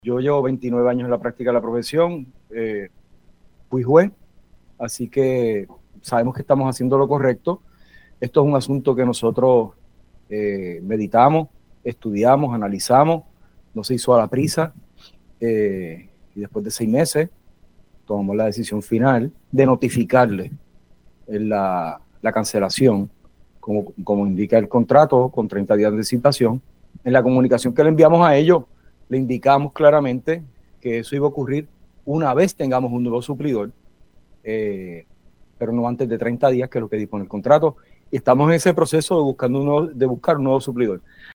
El secretario de Corrección, Francisco Quiñones aseguró en Pega’os en la Mañana que hizo lo correcto al informar sobre la cancelación del contrato de servicios médicos de Physician Correctional.